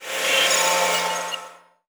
UI Whoosh Notification 4.wav